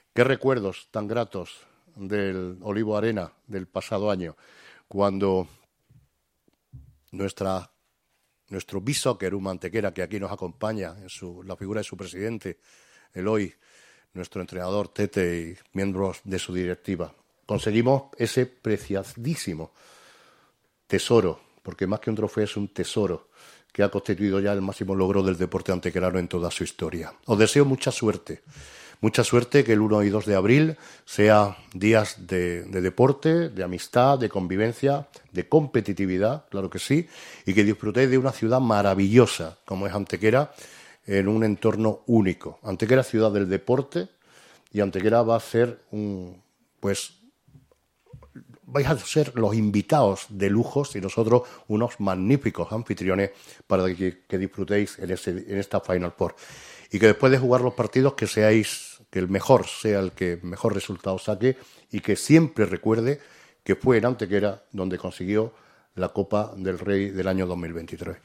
El torneo ha sido presentado hoy en el Museo de los Dólmenes de Antequera.
Cortes de voz